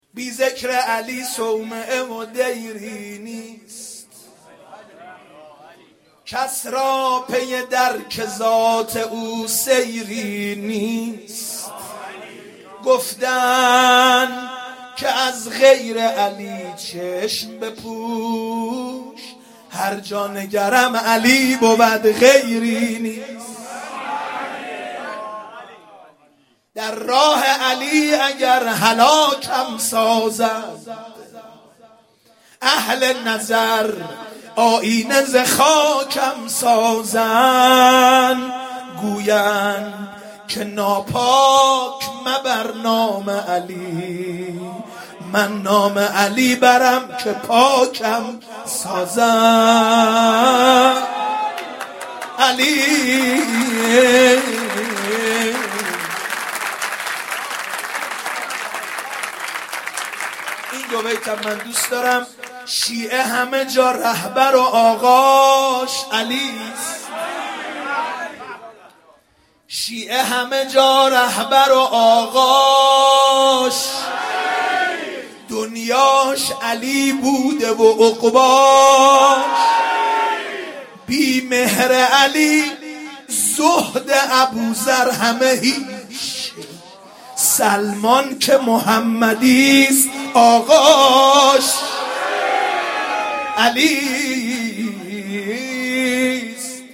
جشن عید مبعث/هیات آل یاسین قم
سرود